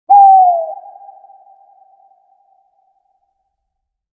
Hoot Owl Sound Effect
Owl hoot at night – single hoot of a nocturnal bird – eerie nighttime sound. Bird sounds.
Hoot-owl-sound-effect.mp3